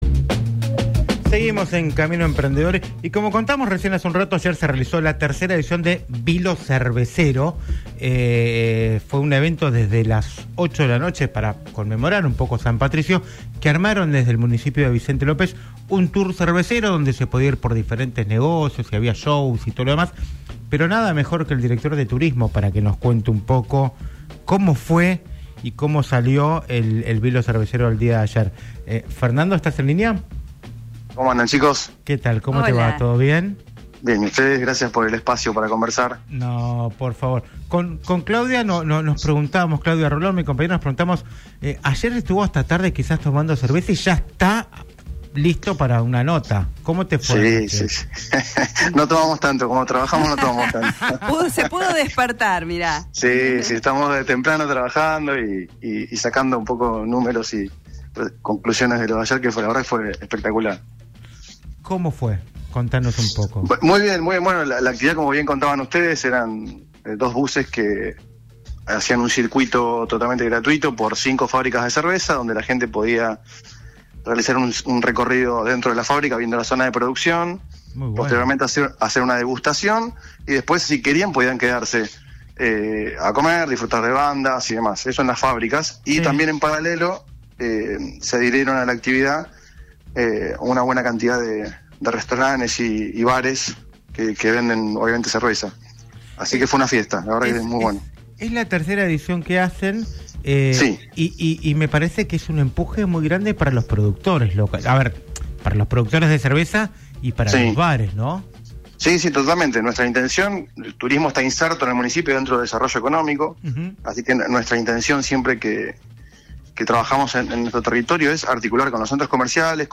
El secretario de Turismo de Vicente López habló con “Camino Emprendedor” (Simphony 91.3) sobre la exitosa Noche de las Cervezas, que tuvo lugar en el distrito.